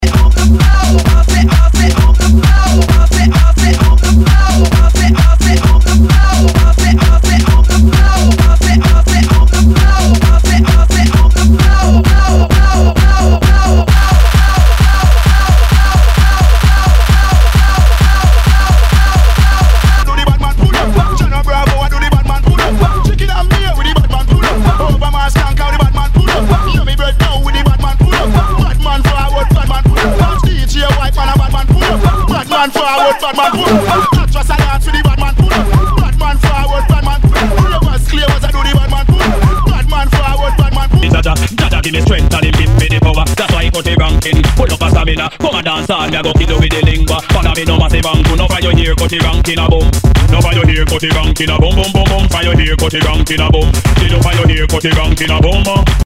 HOUSE/TECHNO/ELECTRO
ナイス！エレクトロ / ファンキー・ハウス！